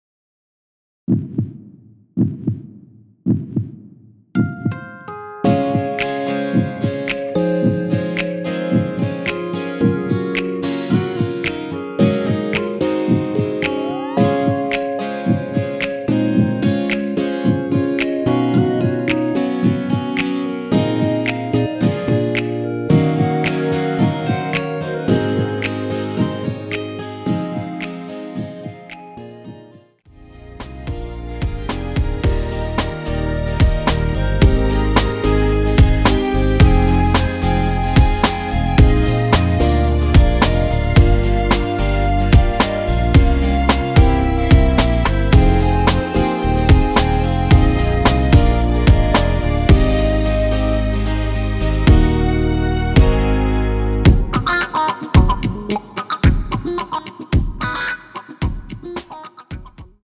원키 멜로디 포함된 MR입니다.(미리듣기 확인)
Db
앞부분30초, 뒷부분30초씩 편집해서 올려 드리고 있습니다.
중간에 음이 끈어지고 다시 나오는 이유는